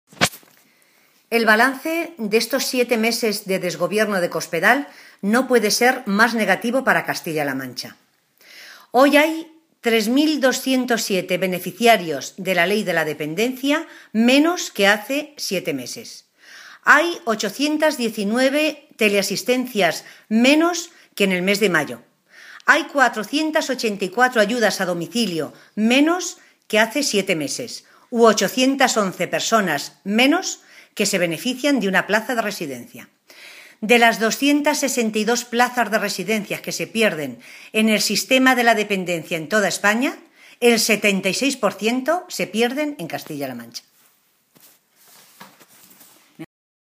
Matilde Valentín, portavoz en materia de Asuntos Sociales del Grupo Parlamentario Socialista
Cortes de audio de la rueda de prensa